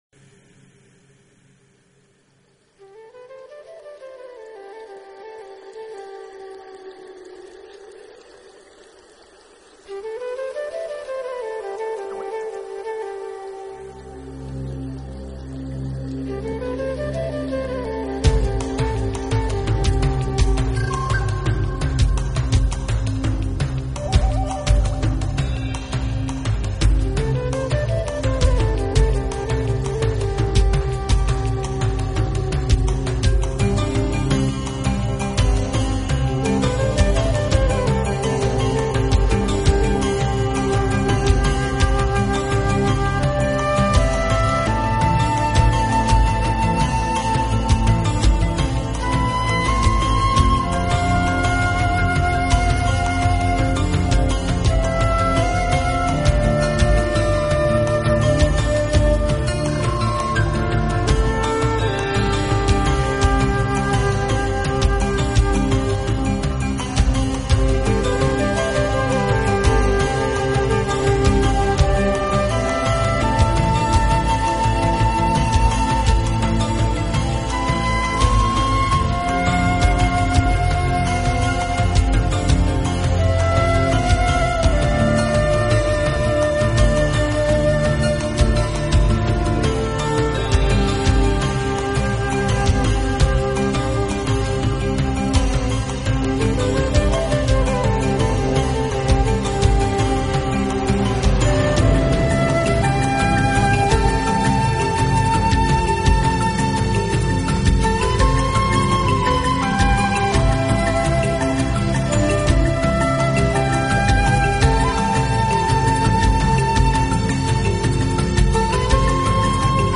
【新世纪长笛】
音乐类型：New Age